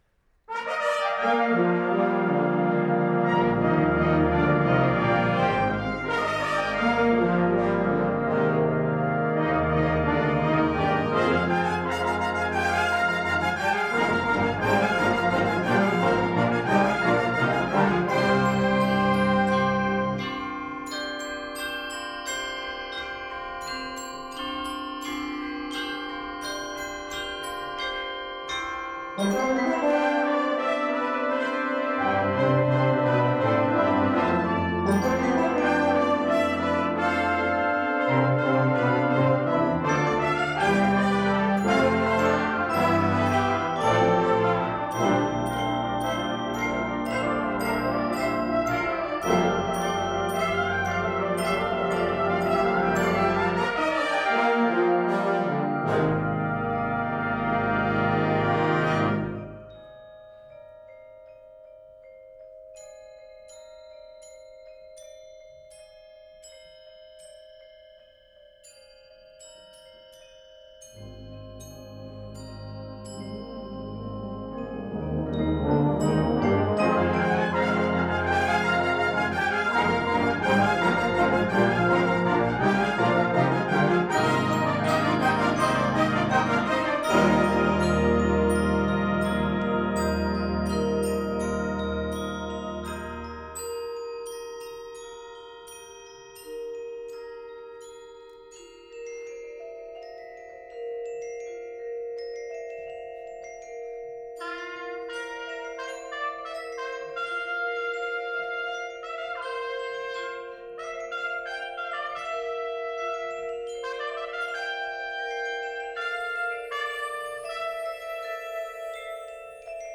Anoka Ramsey Community College, Anoka, MN